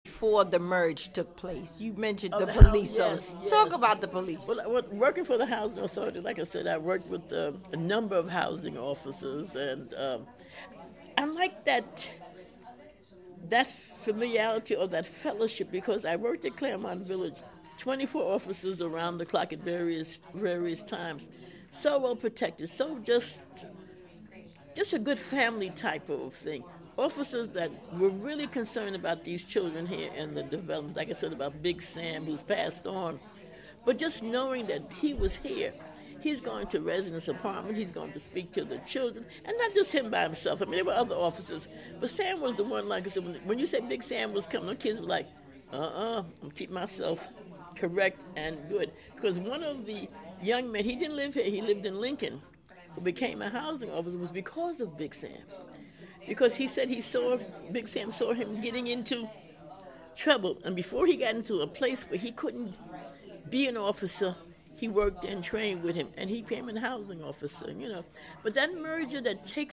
A peer interview format was used to support relationship building and allow residents to hear directly from each other.